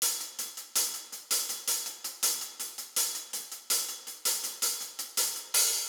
Have Mercy HiHat Loop.wav